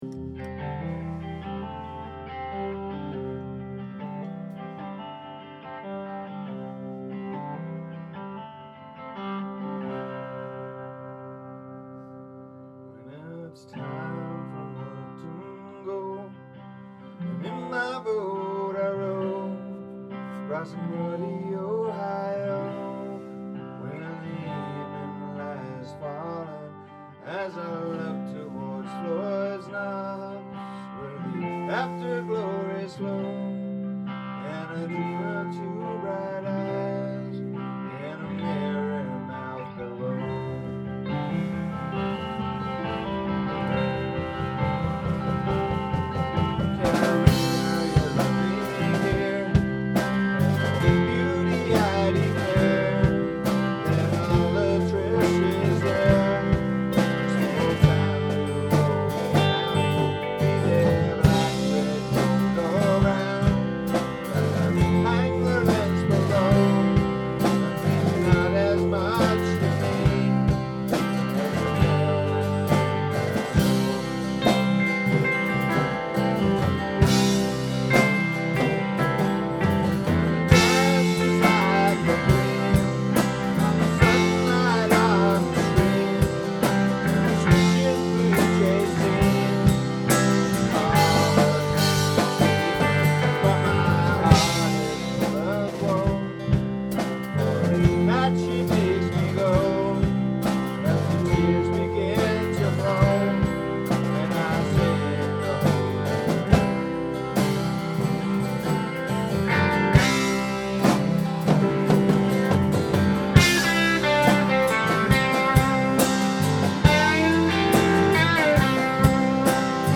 The Rehearsal Recordings